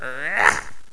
carpet_attack3.wav